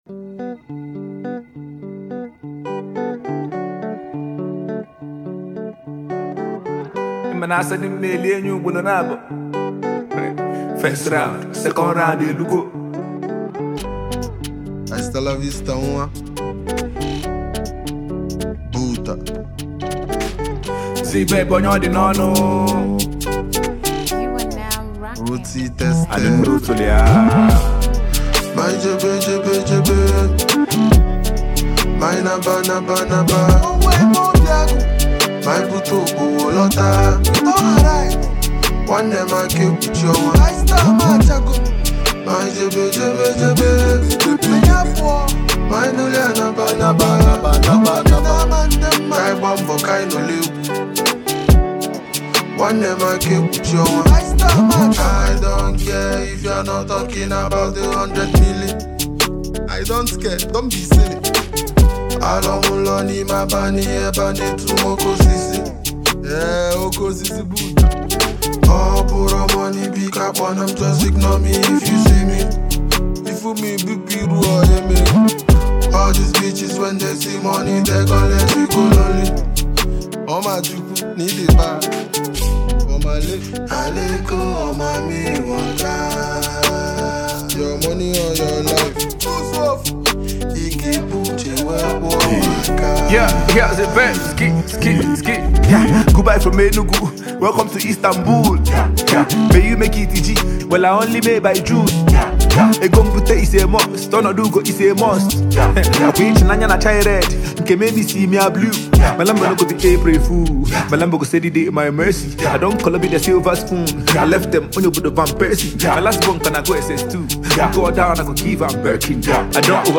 ” blends Afrobeat with contemporary sounds
With its upbeat tempo and catchy sounds